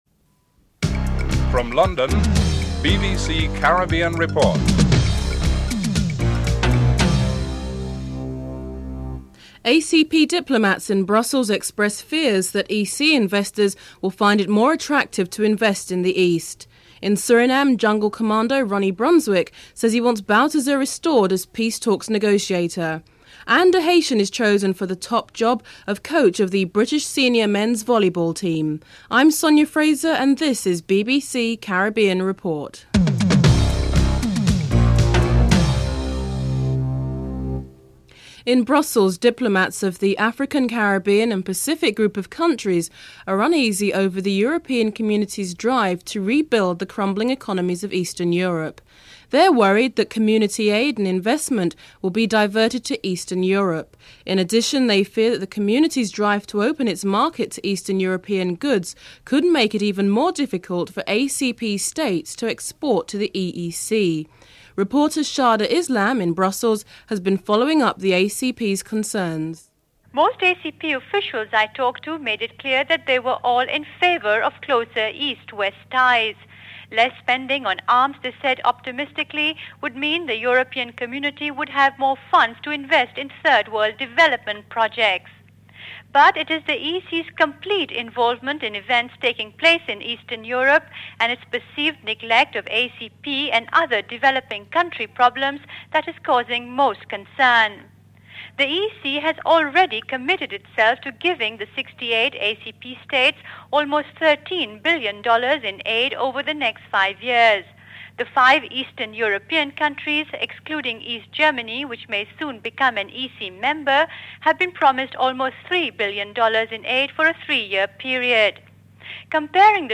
1. Headlines (00:00-00:37)
4. Financial News (06:11-07:57)
5. Dame Nita Barrow, Barbados Ambassador to the United Nations responds to John Compton's assessment of Latin American-Caribbean relations (07:58-09:59)